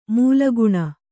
♪ mūla guṇa